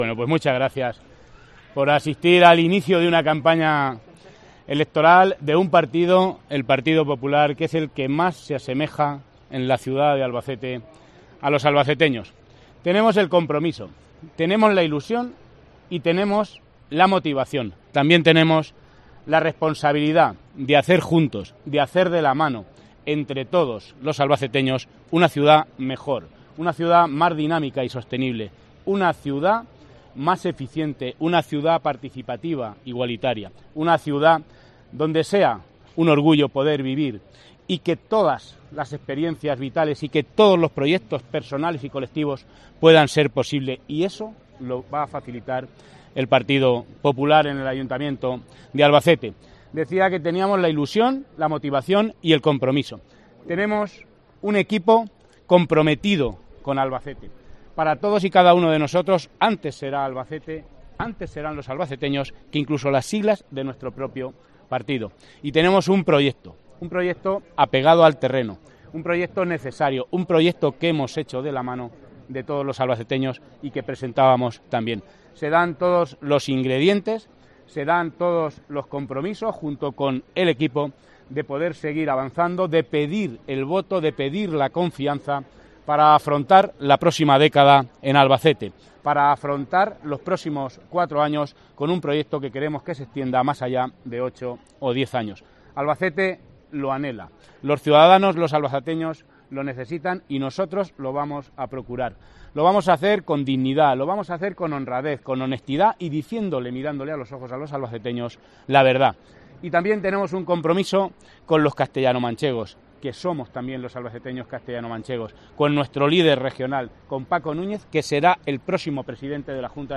El Partido Popular, en el Paseo de la Libertad
Declaraciones Manuel Serrano- Paseo de la Libertad